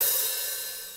CL_OHH1.wav